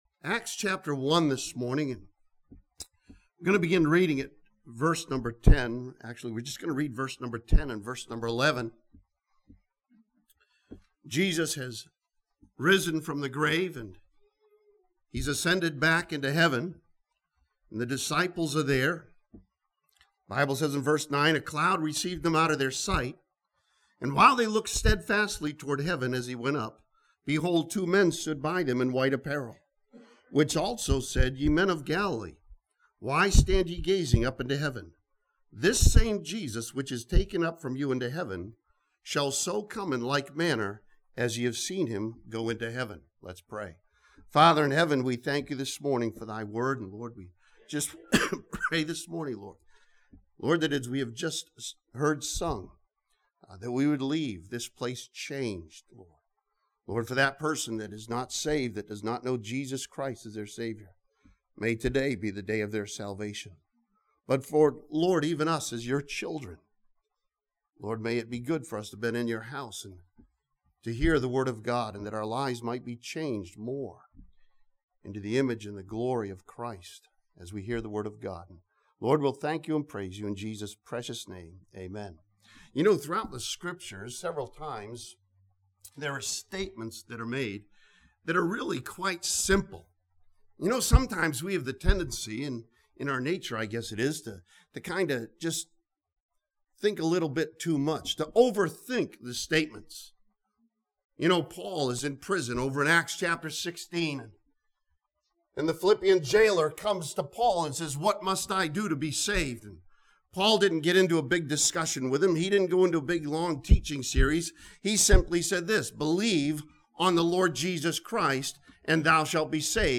This sermon from Acts chapter 1 challenges believers to remember that the Jesus of the past is still the same Jesus as today.